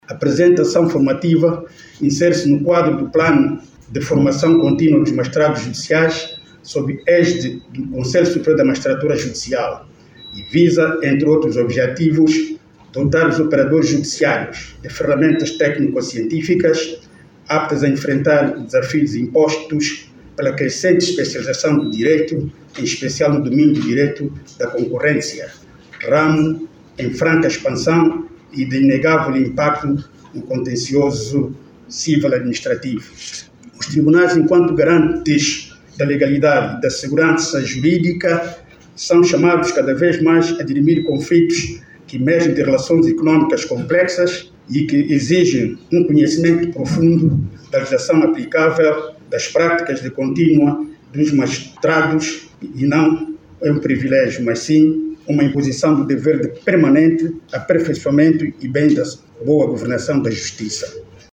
A Autoridade Reguladora da Concorrência – ARC, em parceria com os Conselhos Superiores da Magistratura Judicial e do Ministério Público, realizou, em Luanda, a abertura do primeiro curso de Direito da Concorrência.
Na ocasião, o Presidente do Tribunal da Relação, Vidal Romeu, destacou que a formação contínua constitui uma exigência permanente para garantir segurança jurídica e uma justiça mais eficaz.